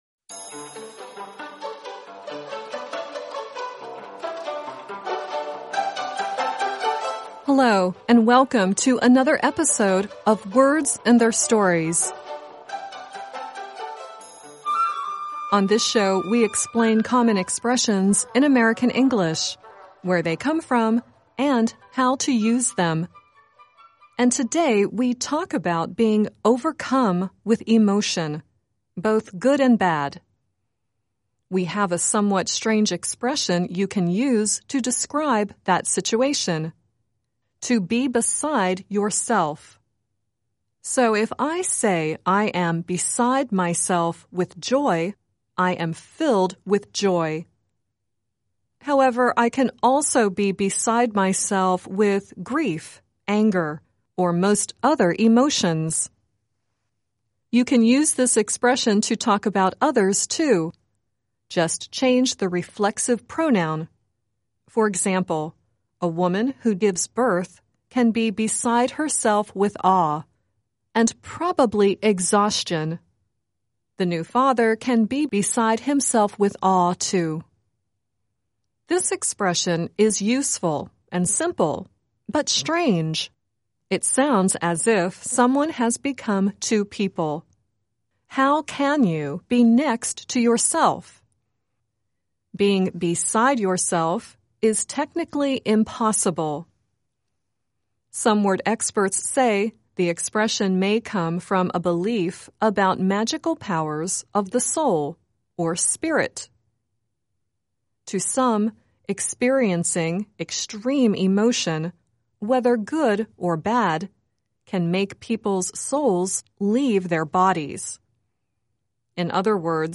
The song at the end is Ricky Skaggs singing “I’m Beside Myself.